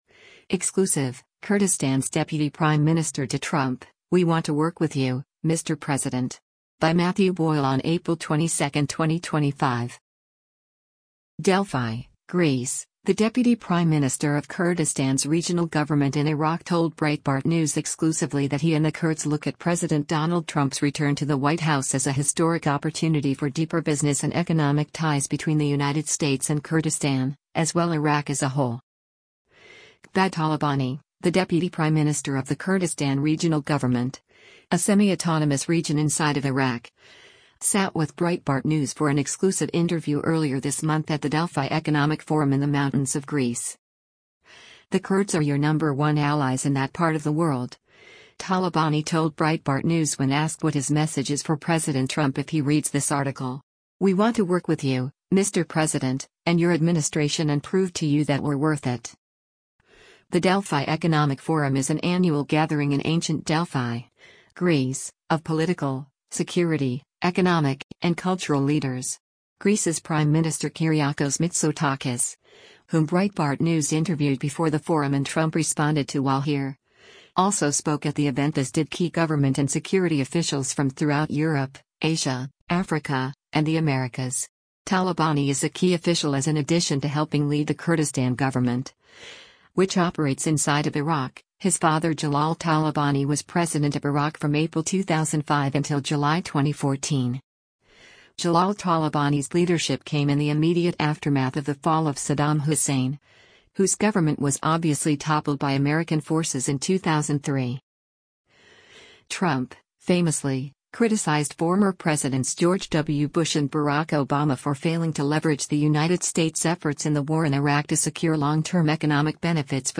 Qubad Talabani, the Deputy Prime Minister of the Kurdistan Regional Government—a semi-autonomous region inside of Iraq—sat with Breitbart News for an exclusive interview earlier this month at the Delphi Economic Forum in the mountains of Greece.